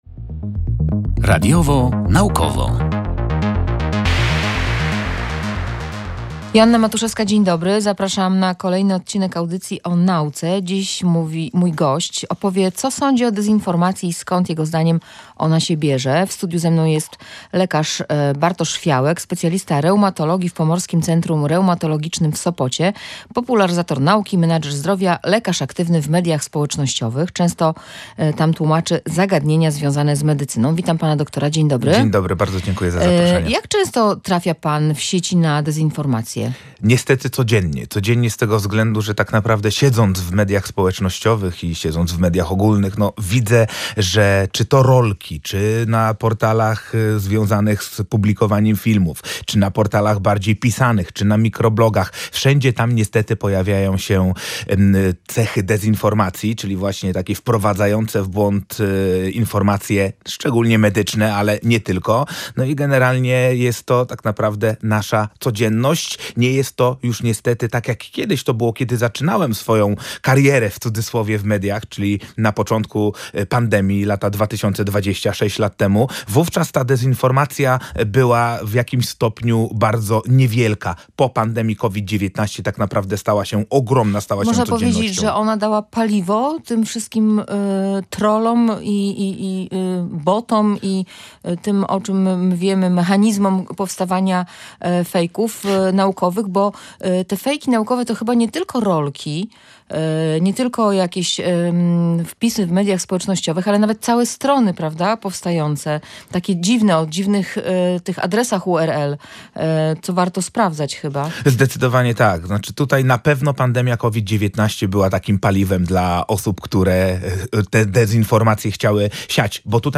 Lekarz na początku rozmowy przyznał, że z dezinformacją niestety spotyka się codziennie.